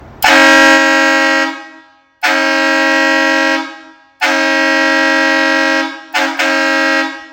12v – Hornblasters 2-Chime 3-Liter Air Horn Kit